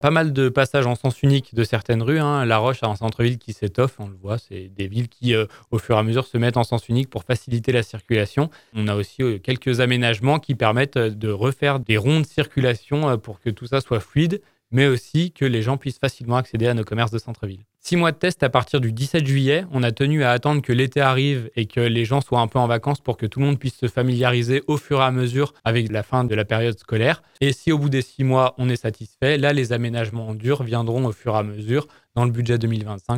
Pierrick Ducimetière, le maire de La Roche-sur-Foron :